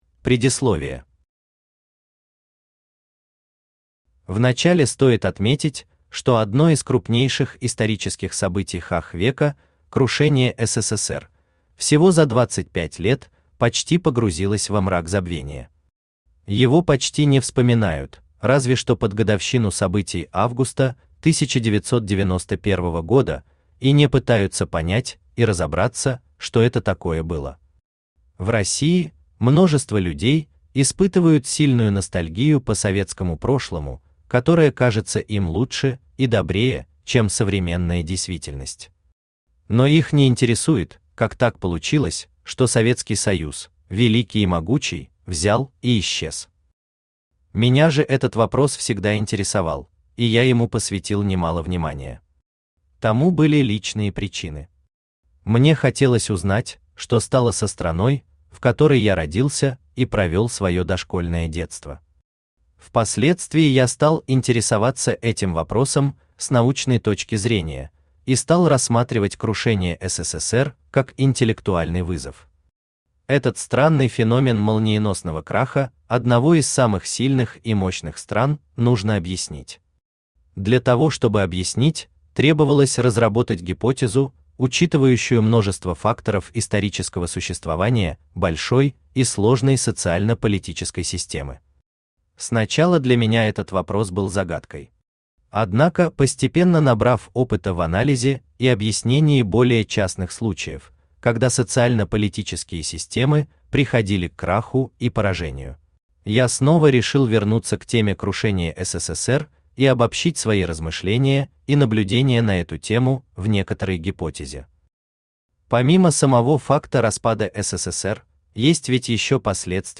Крушение СССР и путинская Россия Автор Дмитрий Николаевич Верхотуров Читает аудиокнигу Авточтец ЛитРес.